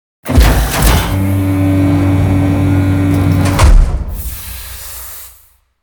Scrape3.wav